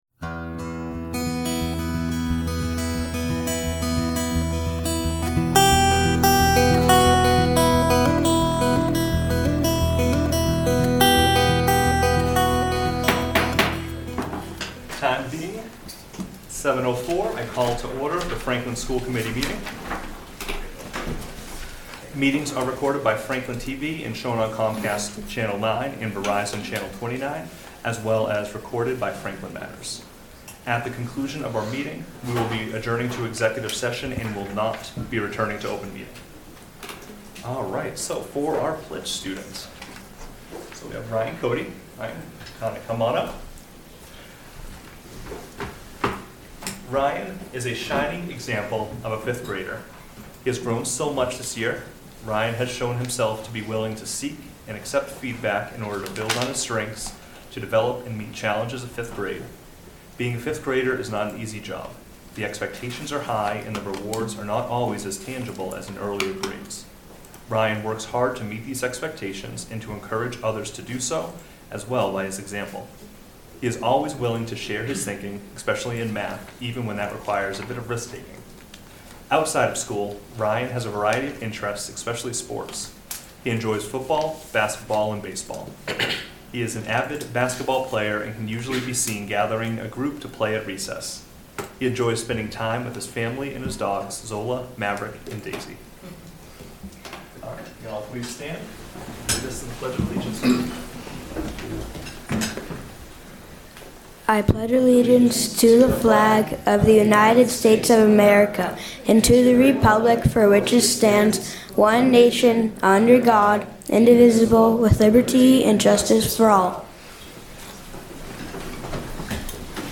This session shares the Franklin (MA) School Committee meeting held on Tuesday, April 8, 2025. 7 members participated, 6 in Council Chambers.1 remote (Sompally).